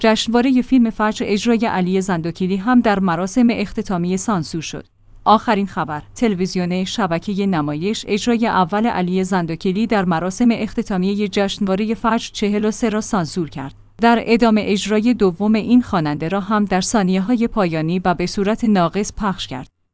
جشنواره فیلم فجر/ اجرای علی زندوکیلی هم در مراسم اختتامیه سانسور شد!